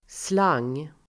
Uttal: [slang:]